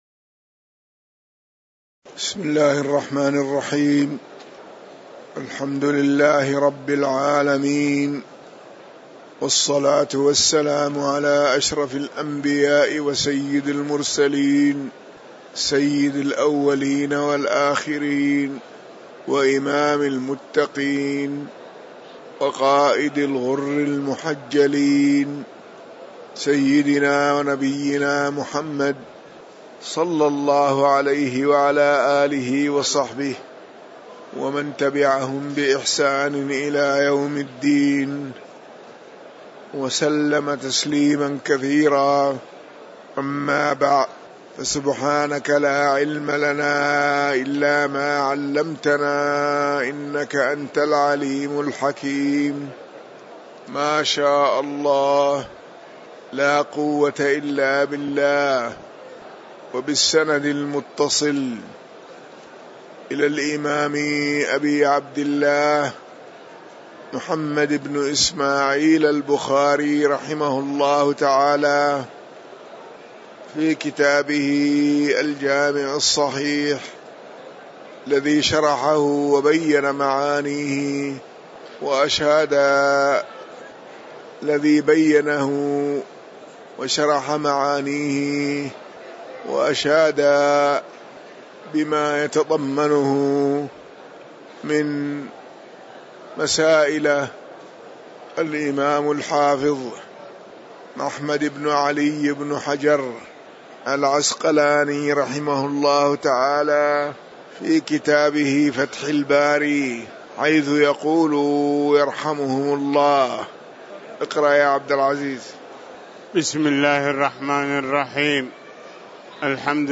تاريخ النشر ٢٩ جمادى الأولى ١٤٤٠ هـ المكان: المسجد النبوي الشيخ